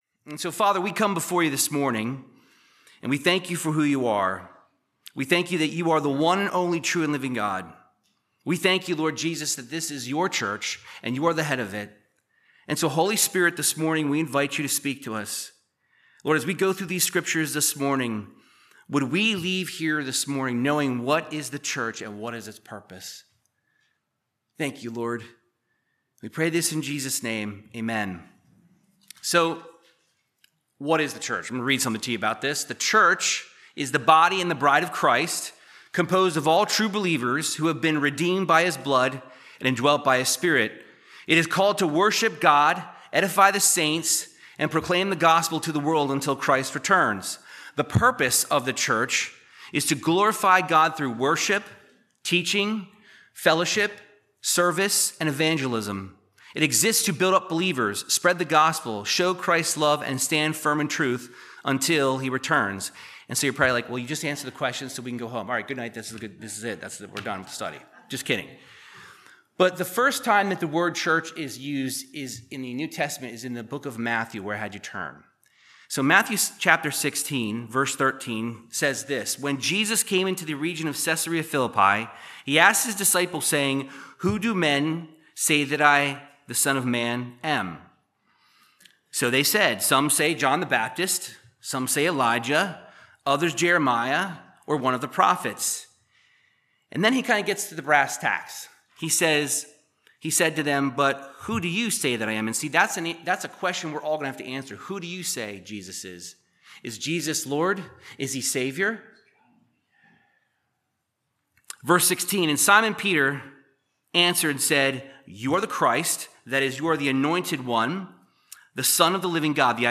Bible Teaching on what is the Church and what is its purpose